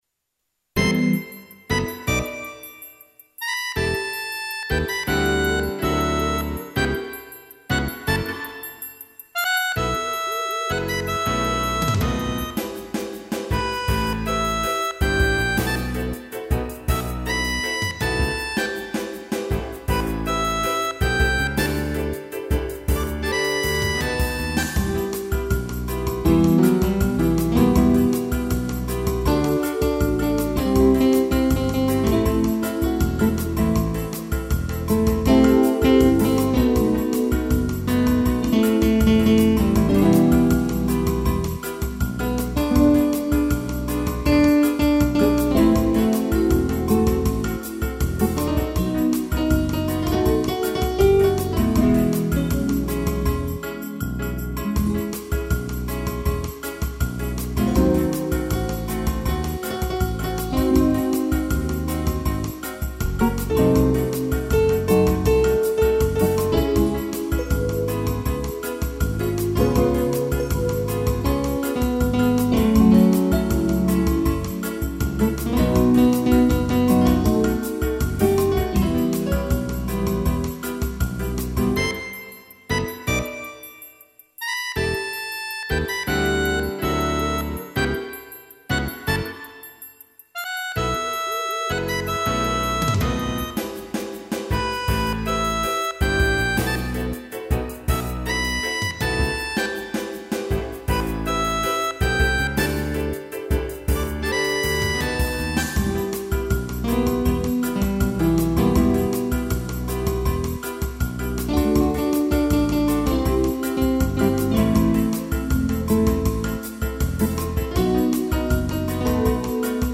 instrumental Saudade